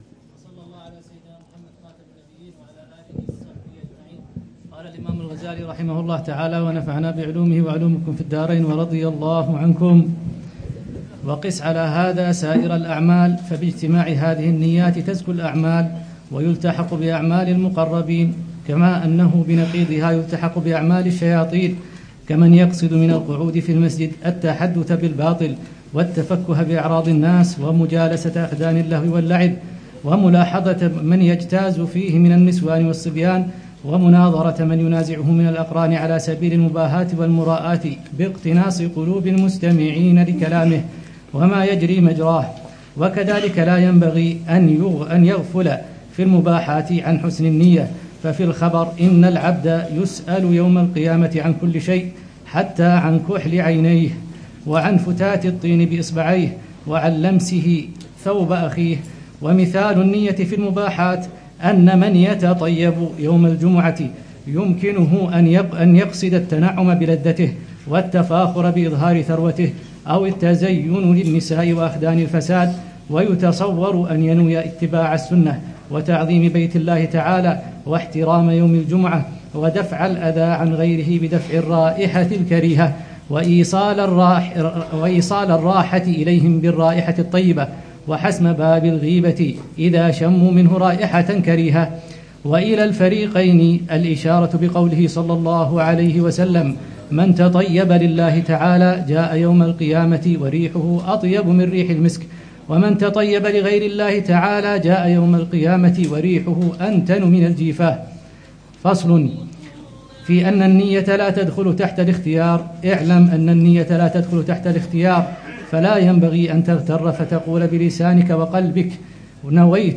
الدرس السابع والثلاثون للعلامة الحبيب عمر بن محمد بن حفيظ في شرح كتاب: الأربعين في أصول الدين، للإمام الغزالي .